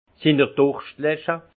Prononciation 68 Munster